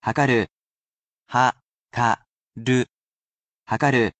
He’ll be here to help sound out these vocabulary words for you.
He’s lovely with tones, as well, and he will read each mora so you can spell it properly in kana.